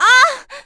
Juno-Vox_Damage_02.wav